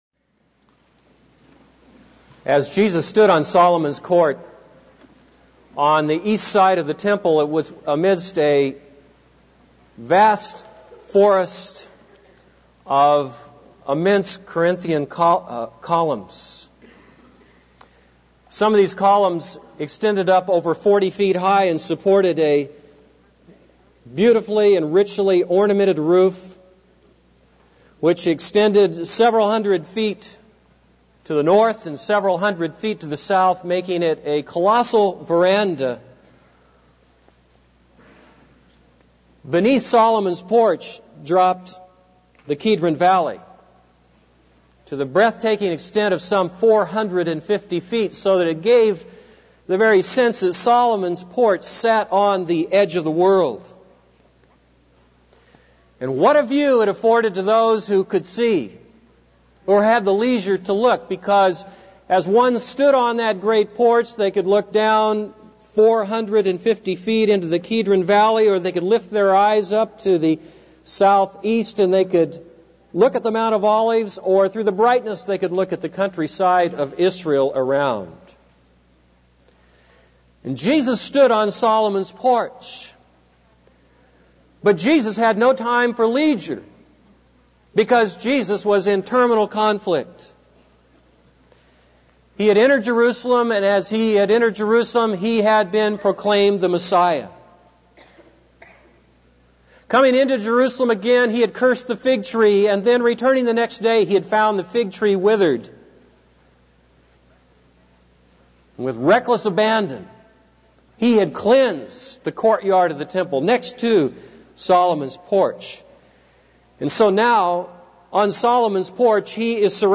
This is a sermon on Mark 12:1-12.